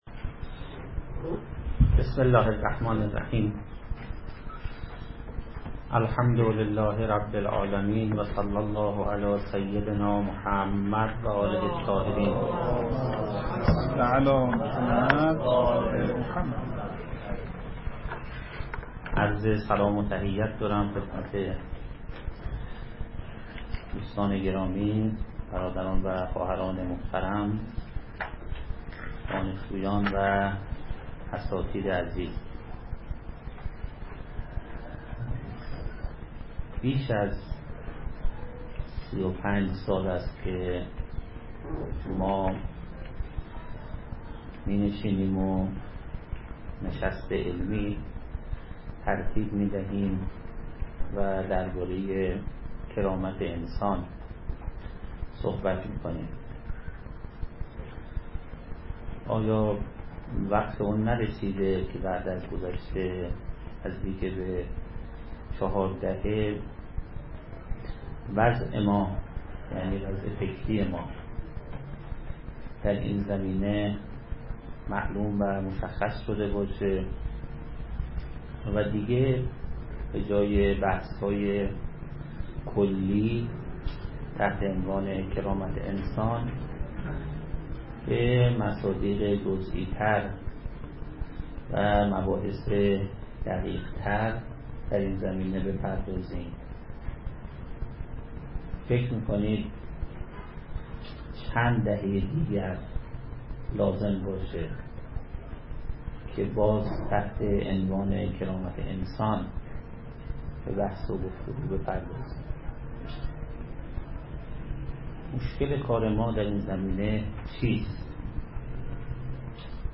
دو گزارش از نشست آزادی و کرامت انسانی در گفتمان سیاسی امام خمینی (ره) در دانشکده حقوق و علوم سیاسی دانشگاه تهران11 خرداد 1395+ فایل صوتی
نشست علمی آزادی و کرامت انسانی در گفتمان سیاسی امام خمینی (ره) دیروز به همت مرکز فرهنگی دانشجویی امام (ره)، ولایت فقیه و انقلاب اسلامی و با همکاری معاونت فرهنگی دانشگاه تهران برگزار شد.